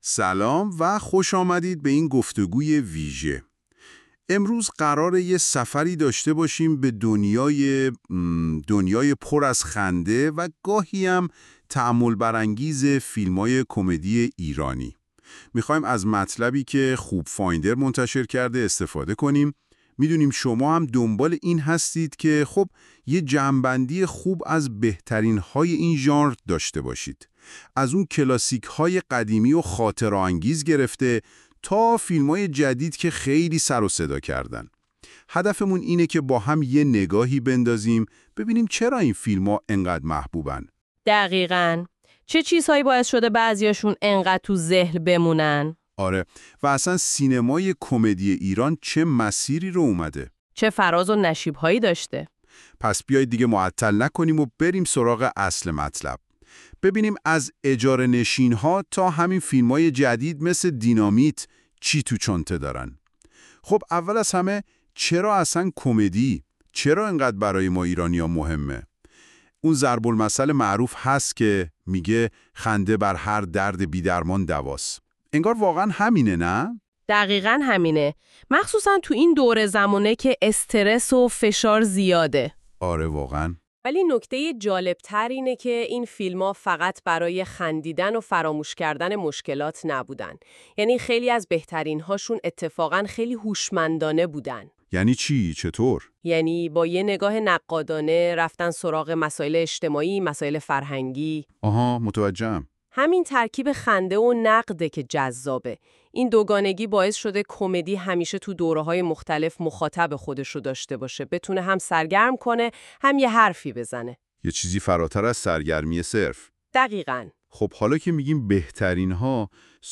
این خلاصه صوتی به صورت پادکست و توسط هوش مصنوعی تولید شده است.